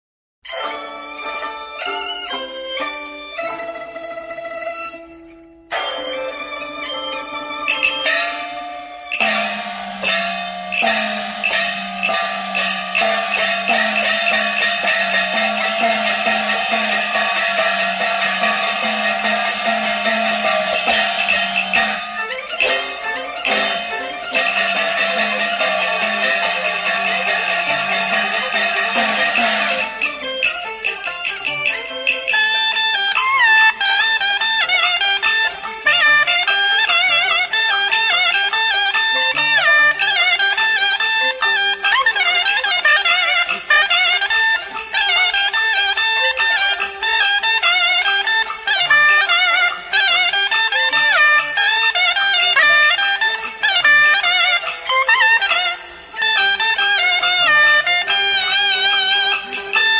唢呐独奏：京剧唱腔联奏